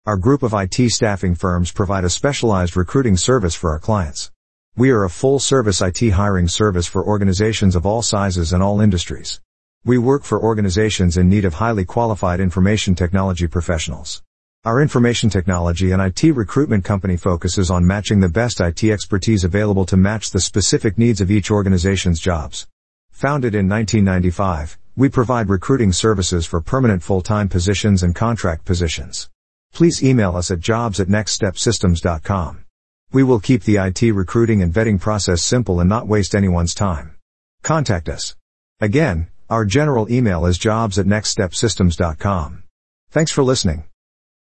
Employers, Listen to Our Artificial Intelligence (AI) on How Our Specialized IT Recruitment Service Can Help You with Your IT Staffing Needs
Please take a moment to listen to an audio file about our IT staffing company employer services generated by Artificial Intelligence (AI). We provide a specialized IT recruitment service covering the entire hiring process from screening through to the final placement.